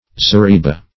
zareeba - definition of zareeba - synonyms, pronunciation, spelling from Free Dictionary
Zareba \Za*re"ba\, n. (Mil.)